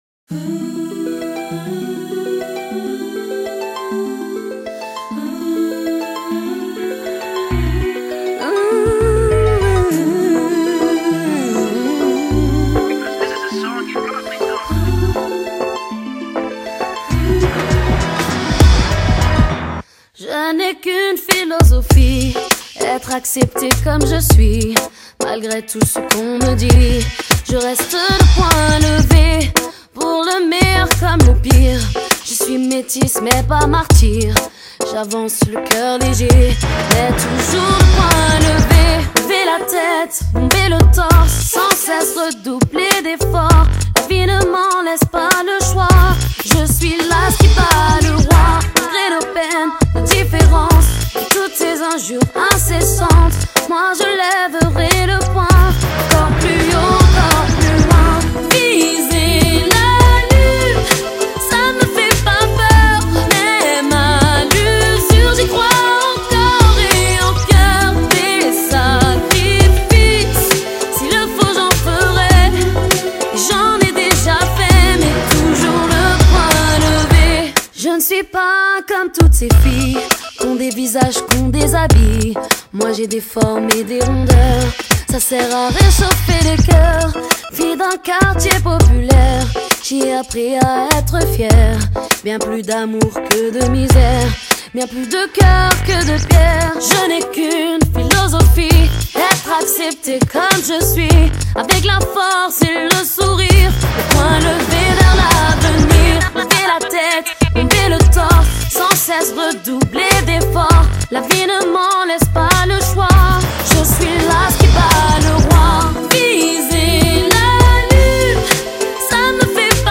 歌手的嗓音极具实力，的确有Mariah Carey的风格。